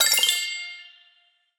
SFX_campanillas.wav